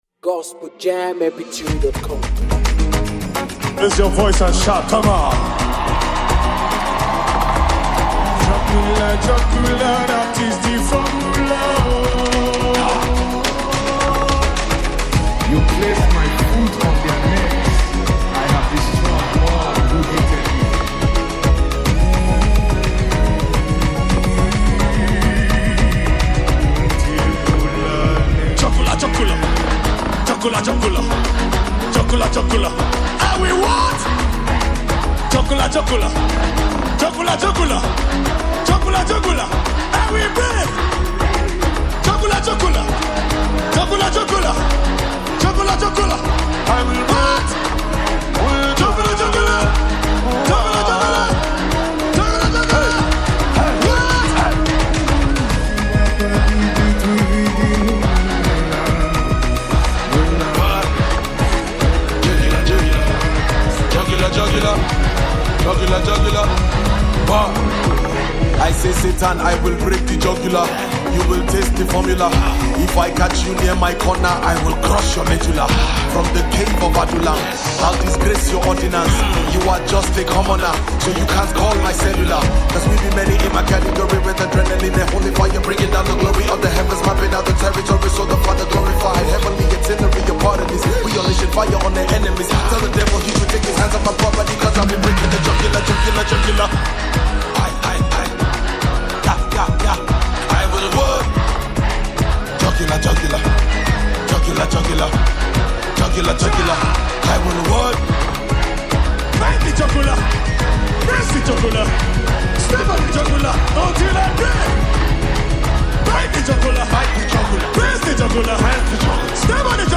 The single is a fiery remix